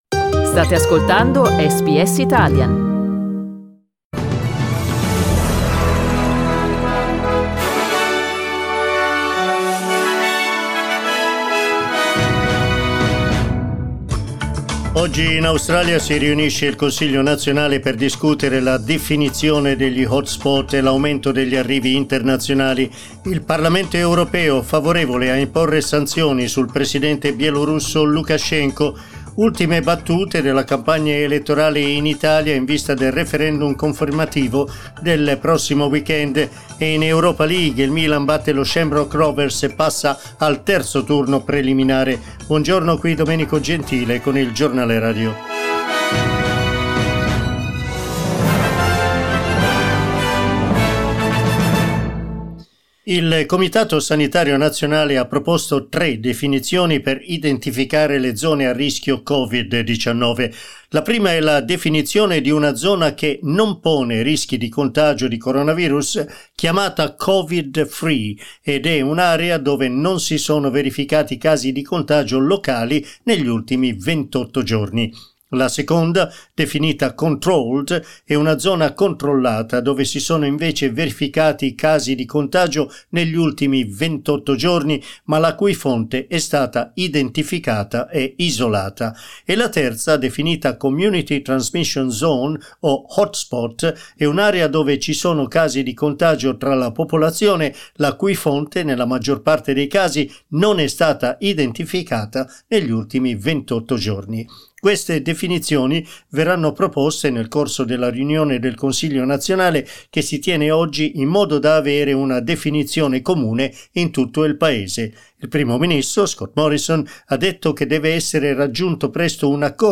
Our 9am news bulletin (in Italian).